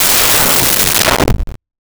Alien Wierdness Descending 01
Alien Wierdness Descending 01.wav